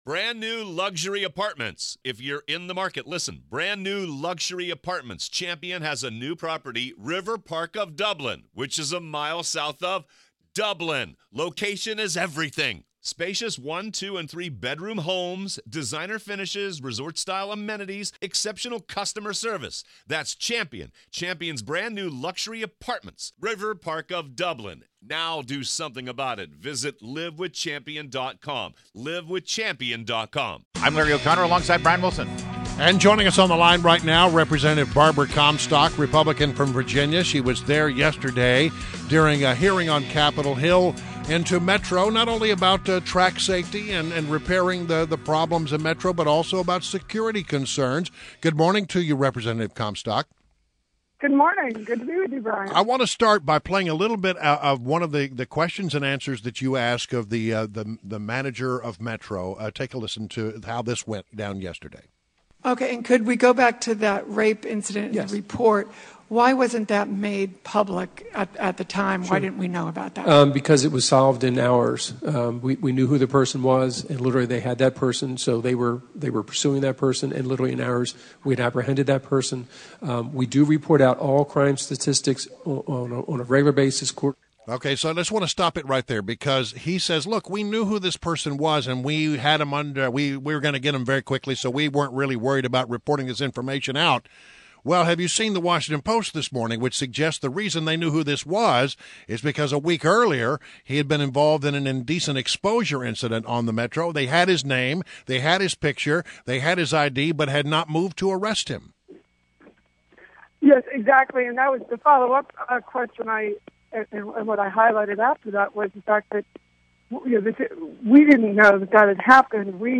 INTERVIEW — CONGRESSWOMAN BARBARA COMSTOCK (R-Virginia)